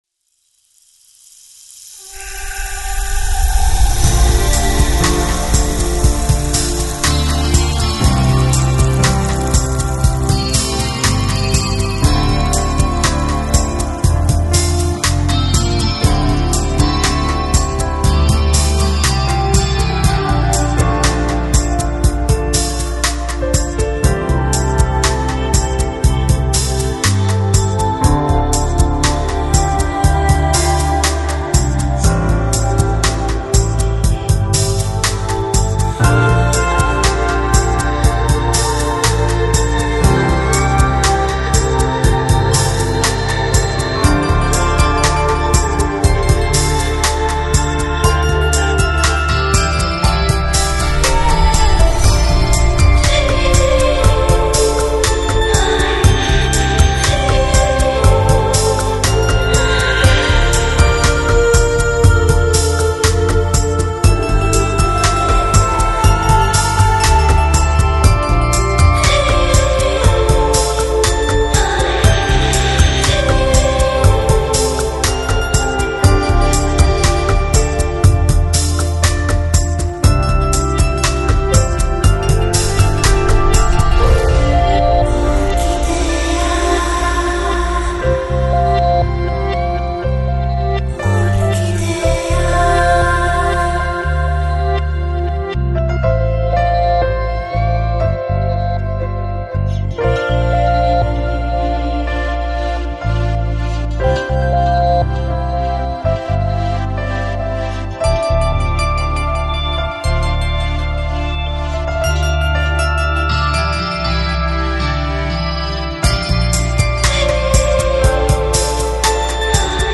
Electronic, Lounge, Chill Out, Downtempo Год издания